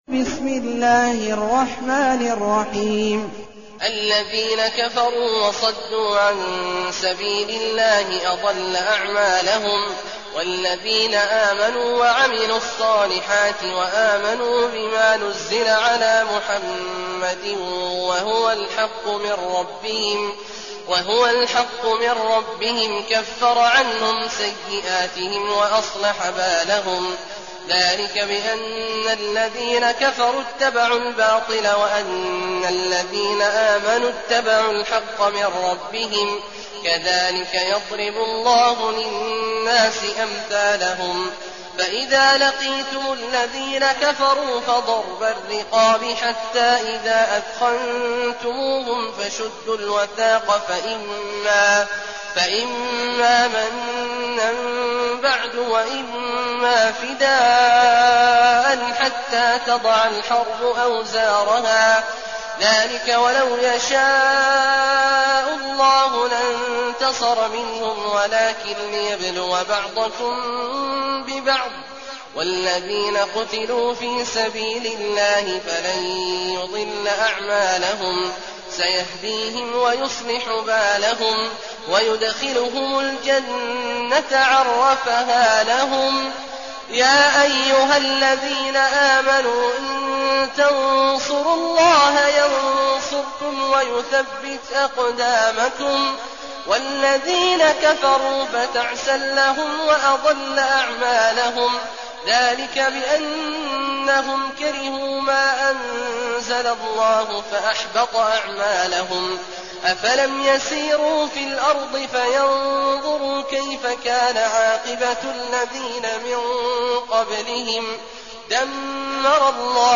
المكان: المسجد النبوي الشيخ: فضيلة الشيخ عبدالله الجهني فضيلة الشيخ عبدالله الجهني محمد